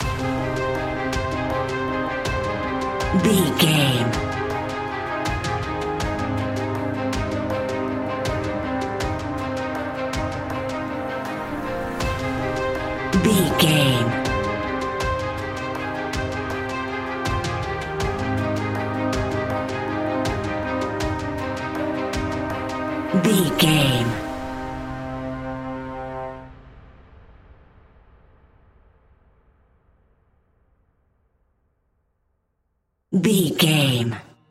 Aeolian/Minor
tension
ominous
dark
haunting
eerie
horror instrumentals